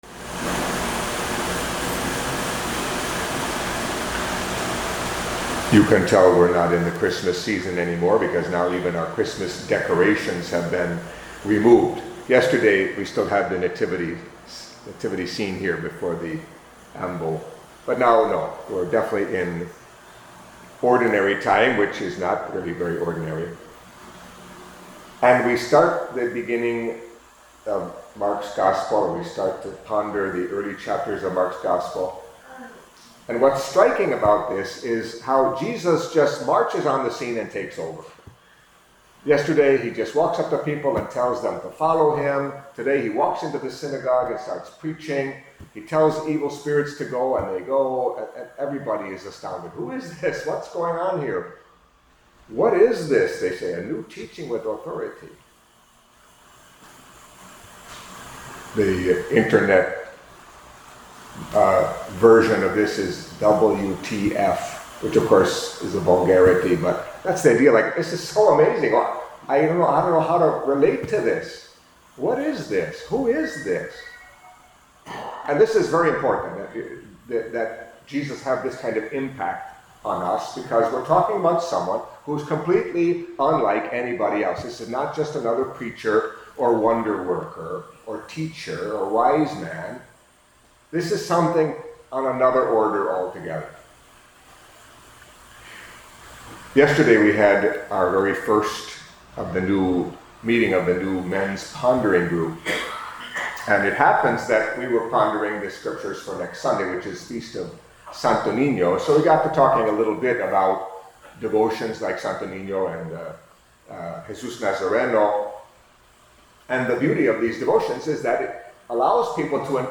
Daily Catholic Mass homilies